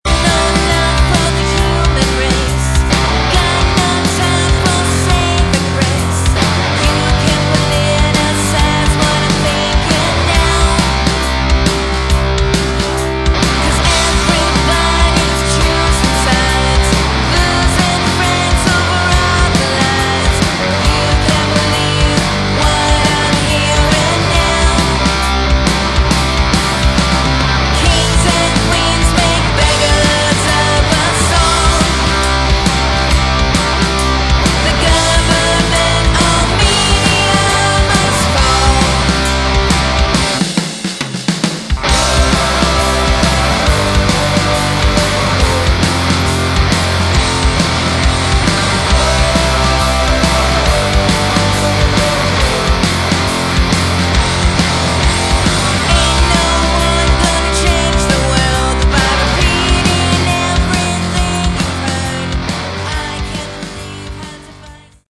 Category: Glam/Punk
vocals
guitar, backing vocals, piano
bass, backing vocals
drums, backing vocals